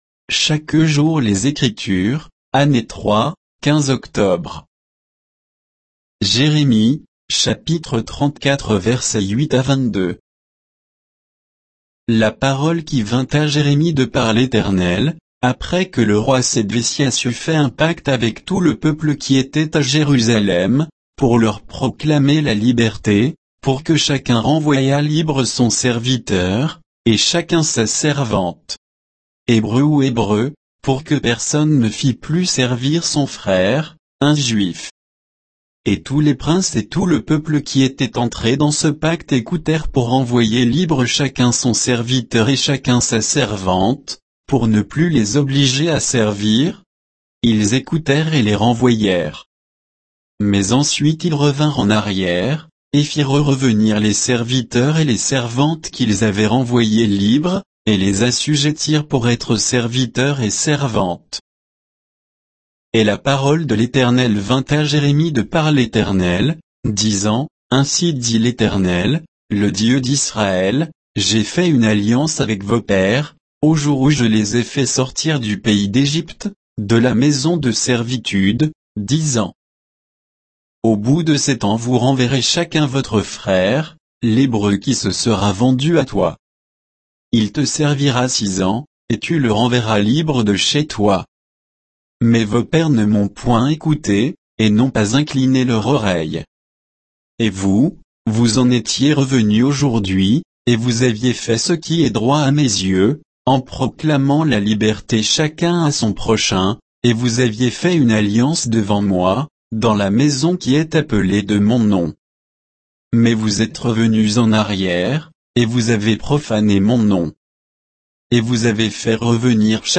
Méditation quoditienne de Chaque jour les Écritures sur Jérémie 34, 8 à 22